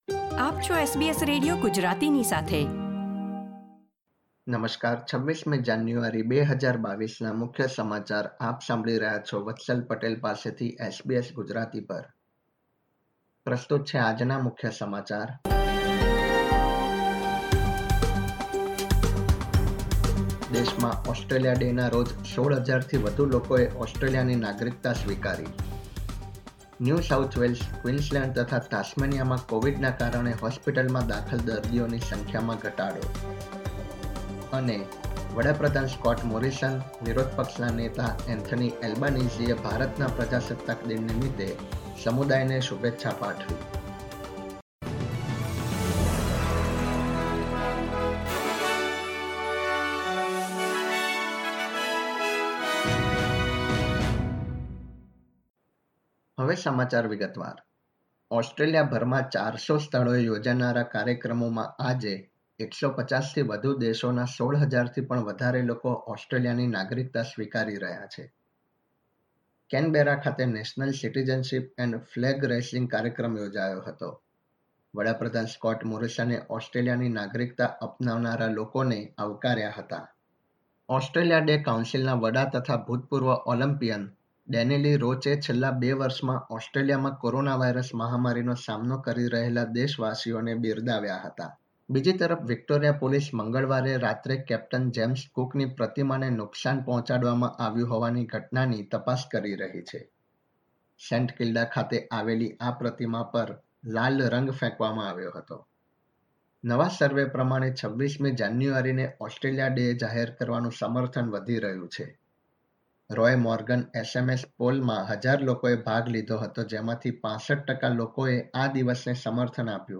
SBS Gujarati News Bulletin 26 January 2022